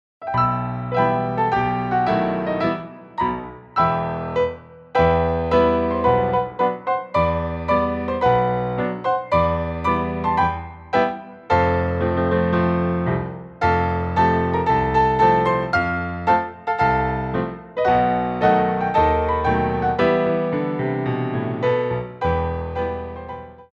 Grand Battements
4/4 (8x8)